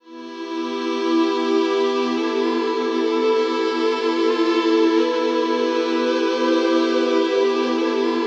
WEEPING 1 -L.wav